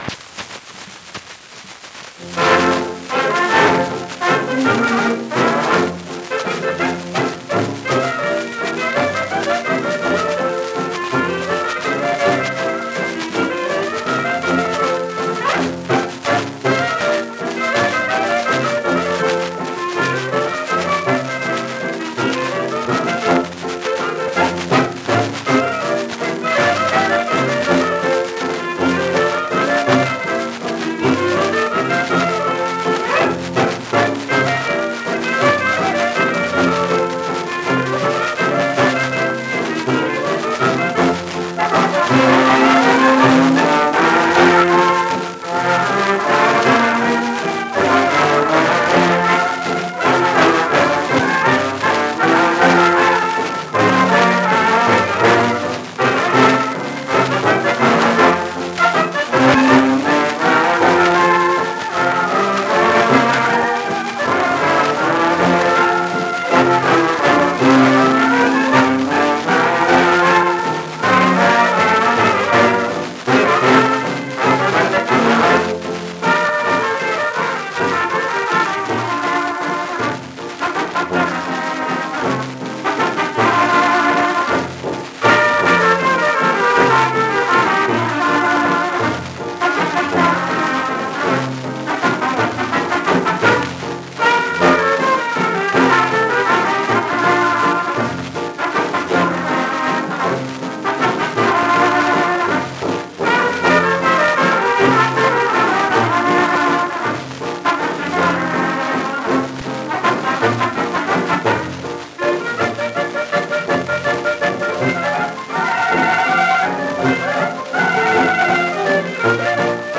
ländler